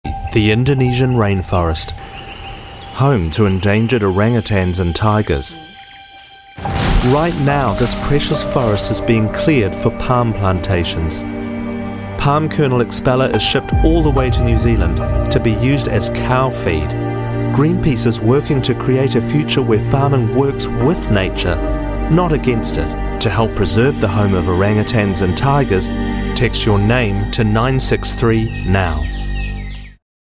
Voice Samples: Indonesian Rain Forest
EN NZ
male